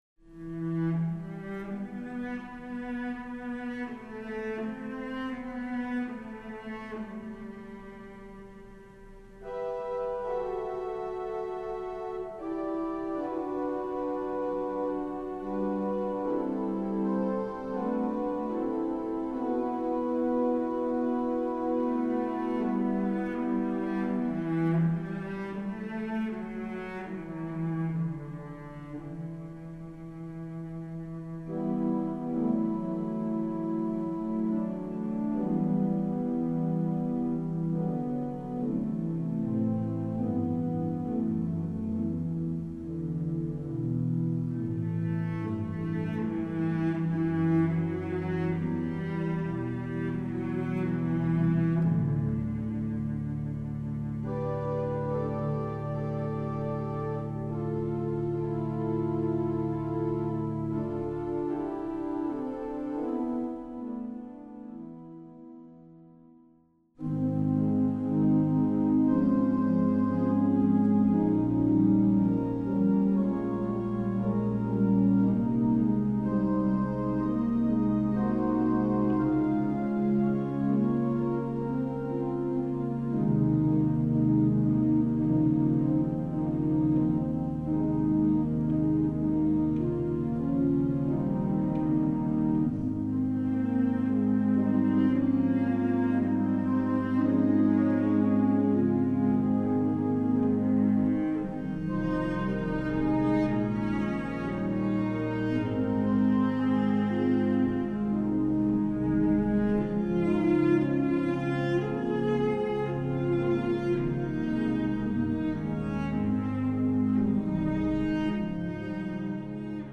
Voicing: Cello W/or